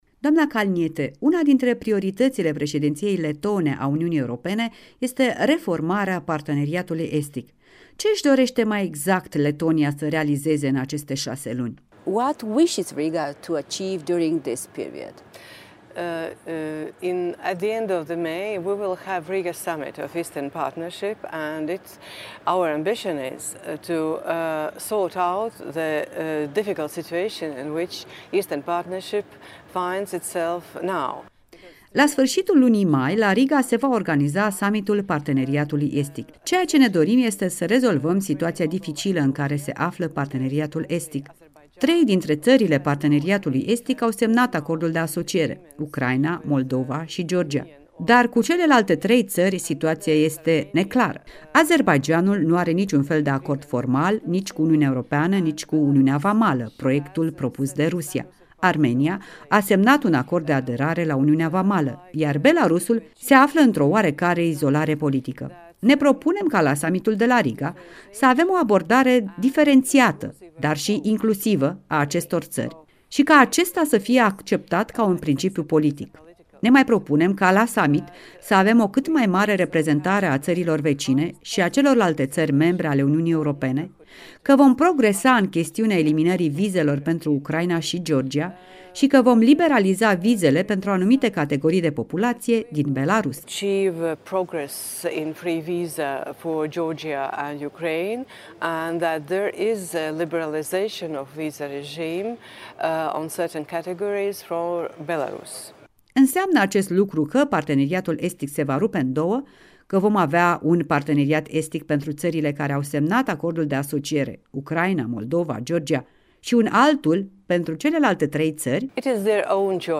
În direct de la Strasbourg cu europarlamentara Sandra Kalniete